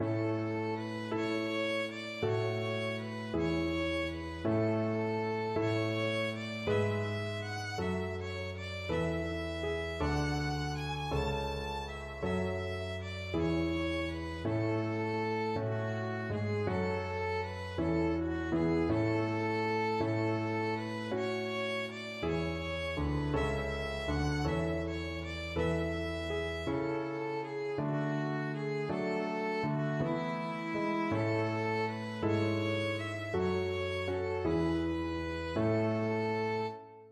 Violin
3/4 (View more 3/4 Music)
E5-A6
Gentle one in a bar (. = c. 54)
A major (Sounding Pitch) (View more A major Music for Violin )
Traditional (View more Traditional Violin Music)